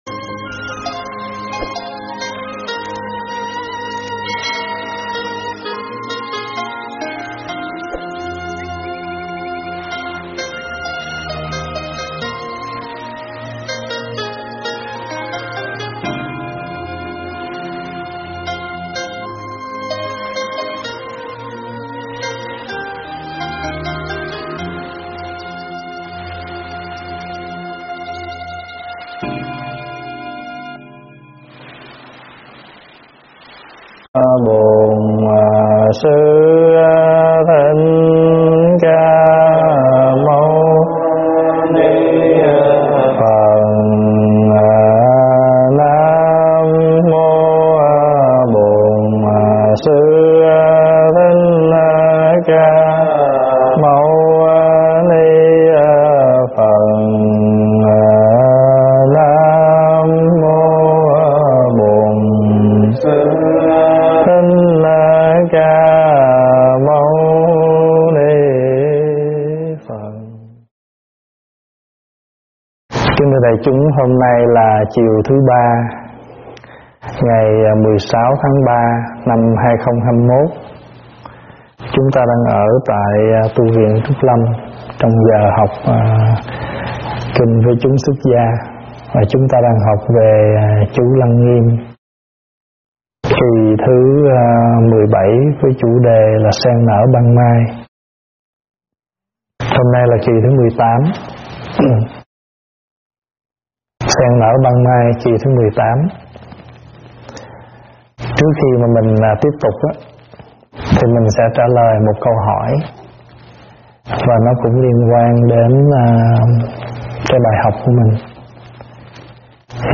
Thuyết pháp Sen Nở Ban Mai 18
giảng tại Tv.Trúc Lâm